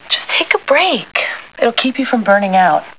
Movie, television and radio clips